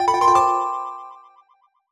jingle_chime_05_positive.wav